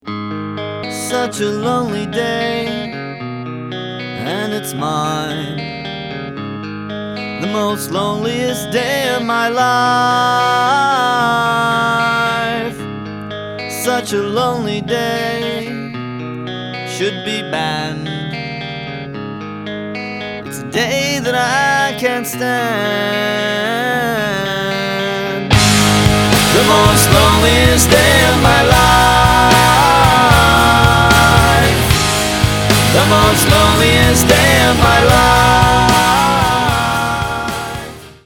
Поп Музыка
грустные # тихие